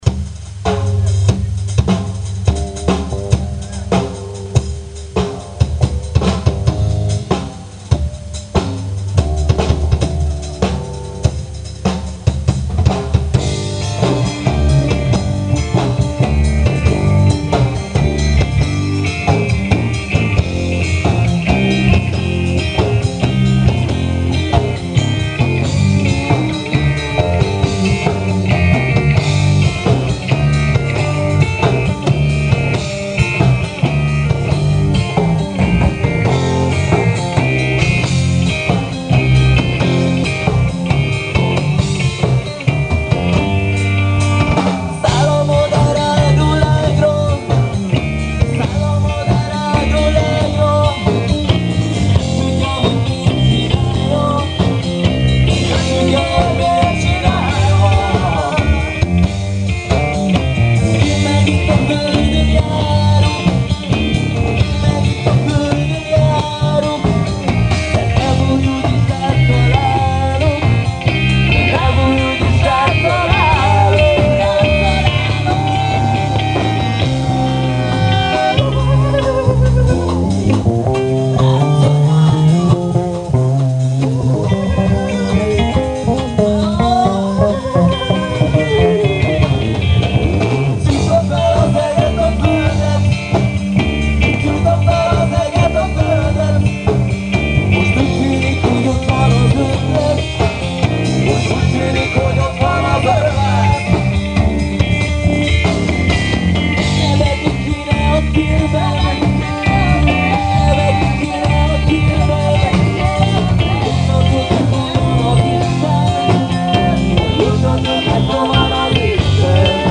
Néhány koncertfelvétel: